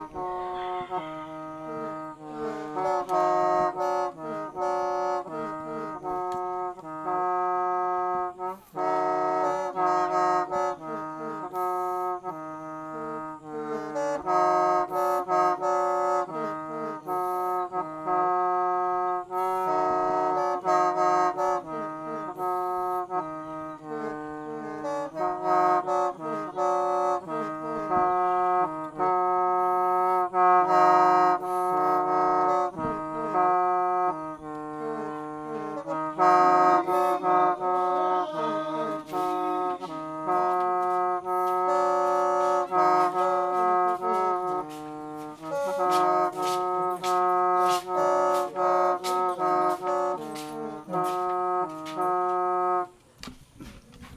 Performance of playing a traditional instrument